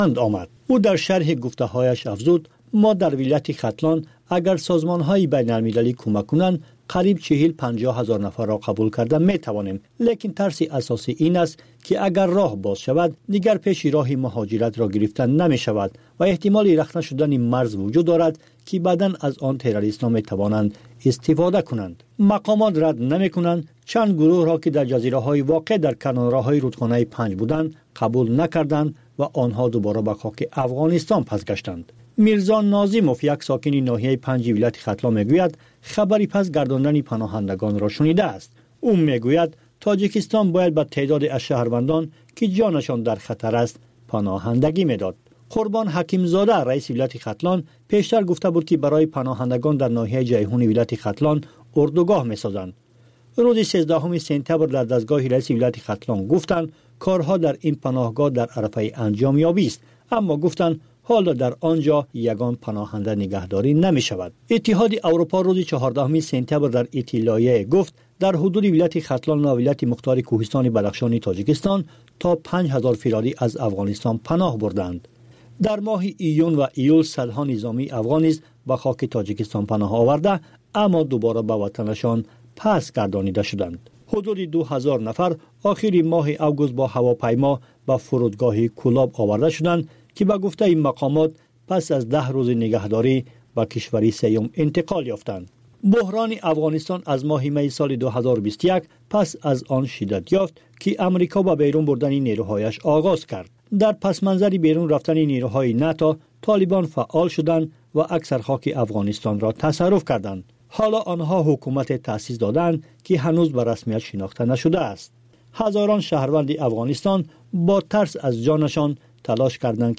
Маҷаллаи шомгоҳӣ